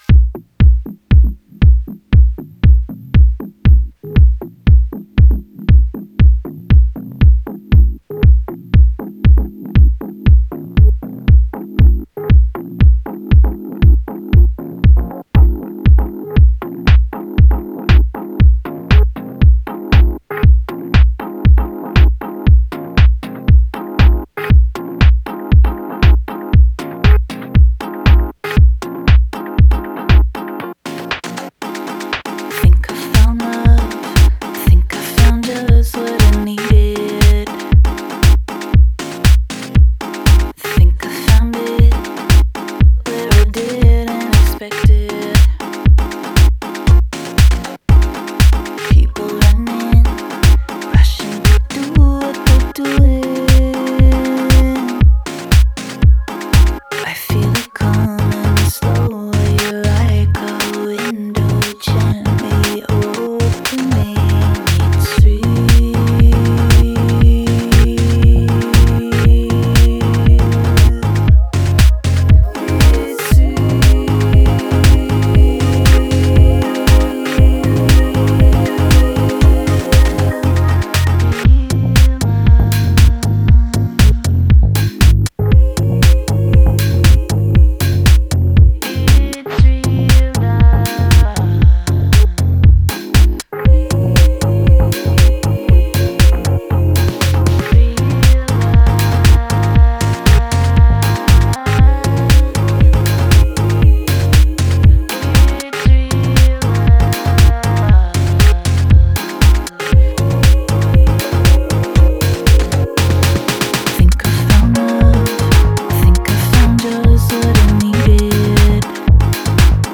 a very balearic-feeling, downtempo track